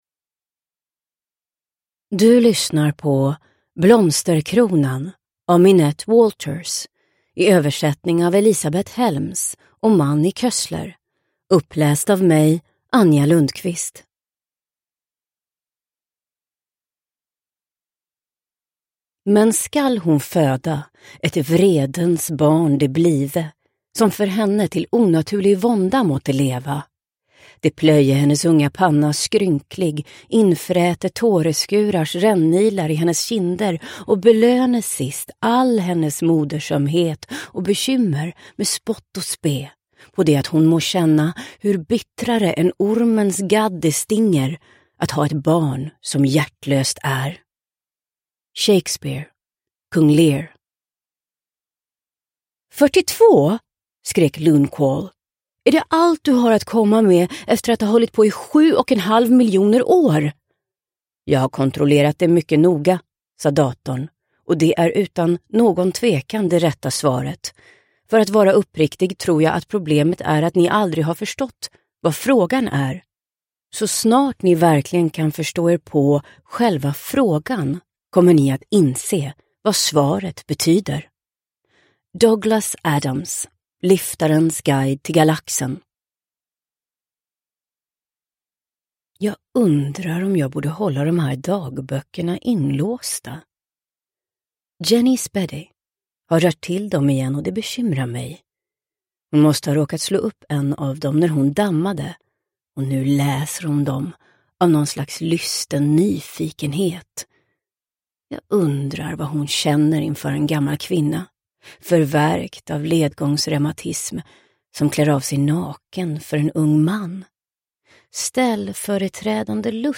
Blomsterkronan – Ljudbok – Laddas ner